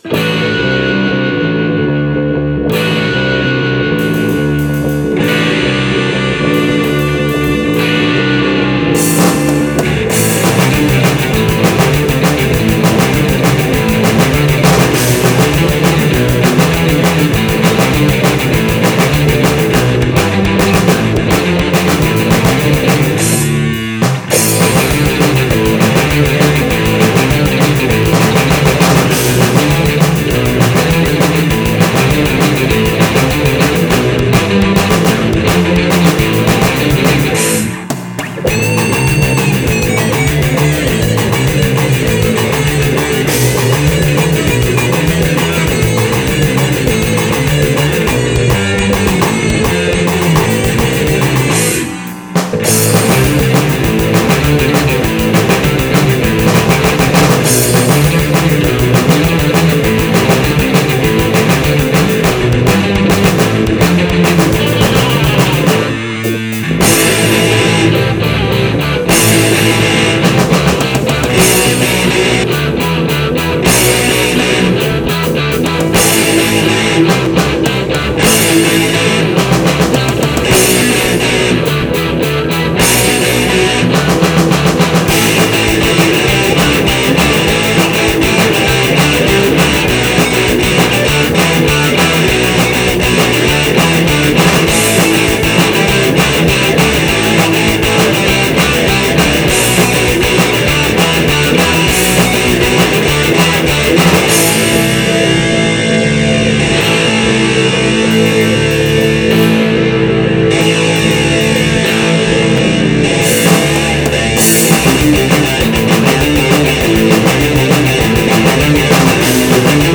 Instrumental
bajo
guitarra, voz
bateria, voz